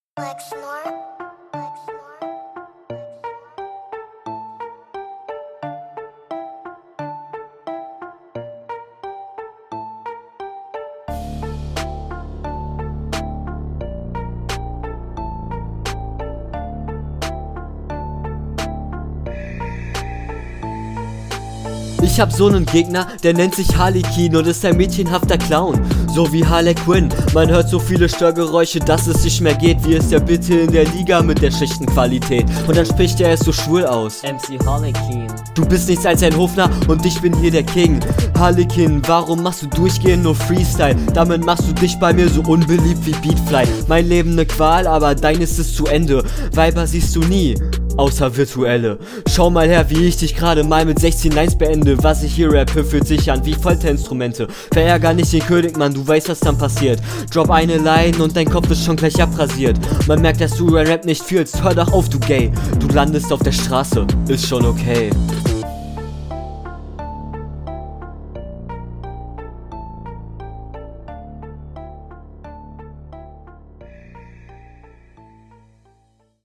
Runde ist auf dem richtigen beat gemacht.
Flow ist ganz in Ordnung du bleibst auf dem Beat.